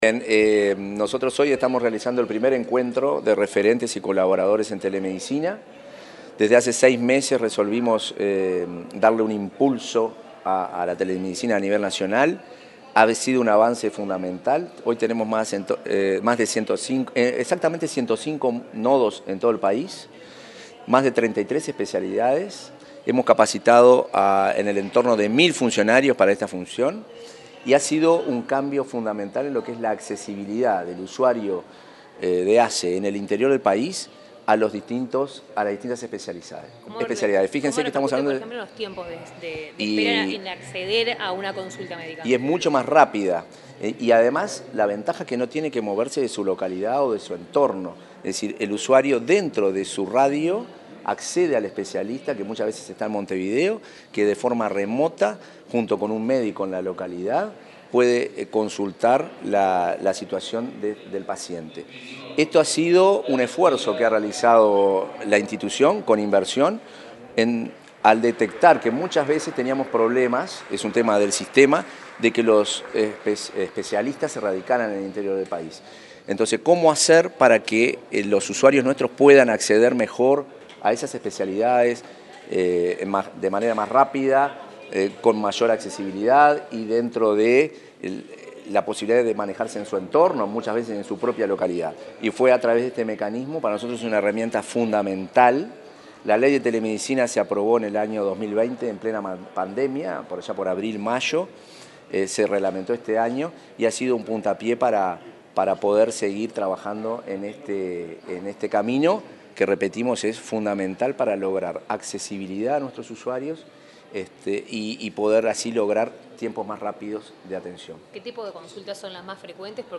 Declaraciones del presidente de ASSE, Marcelo Sosa